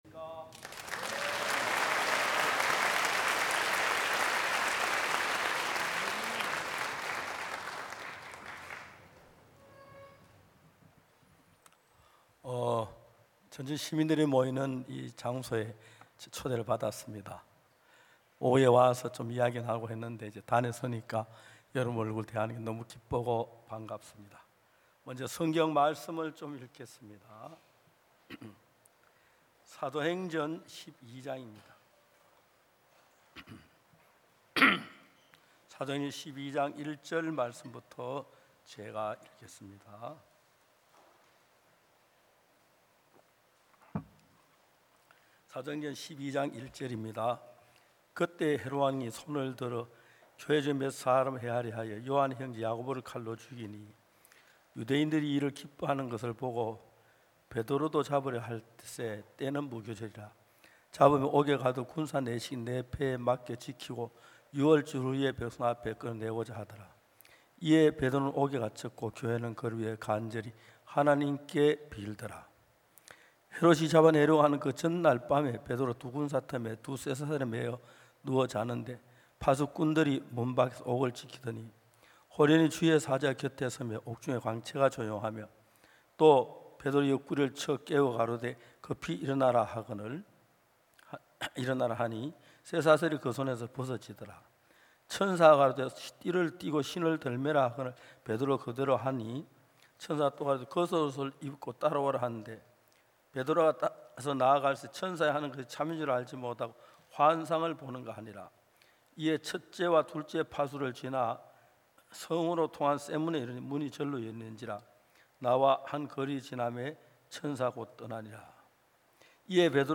성경세미나 설교를 굿뉴스티비를 통해 보실 수 있습니다.